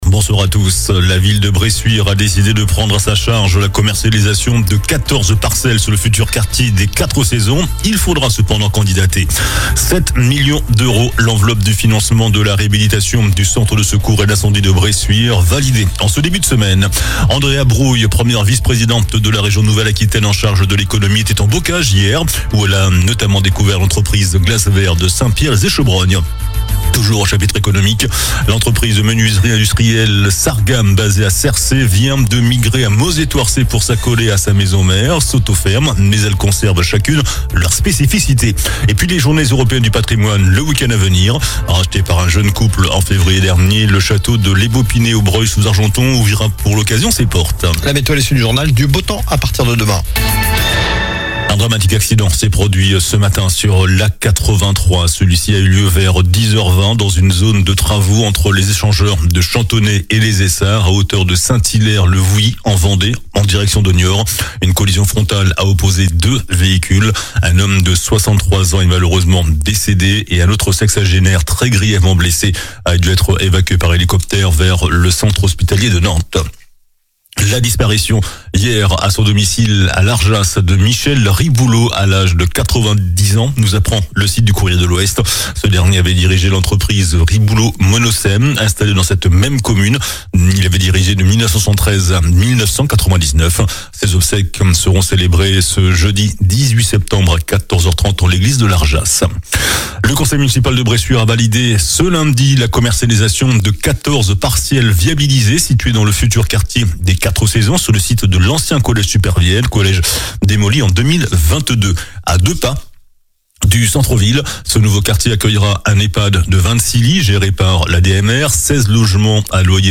JOURNAL DU MARDI 16 SEPTEMBRE ( SOIR )